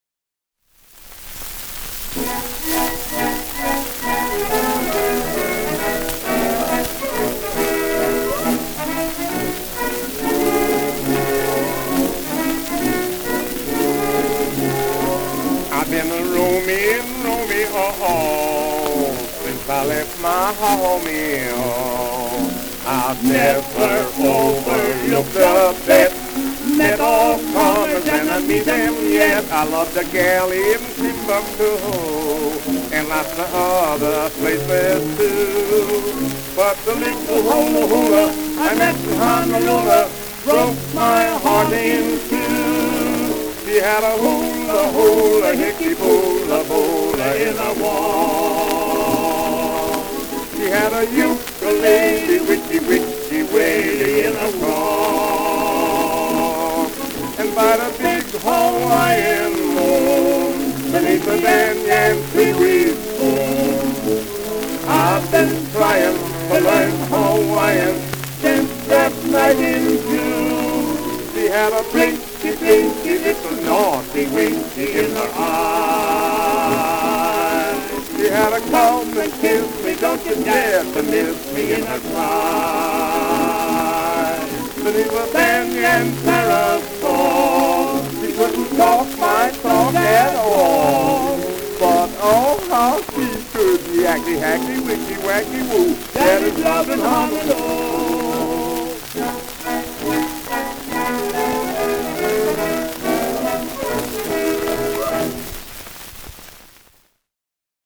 Vocal Duet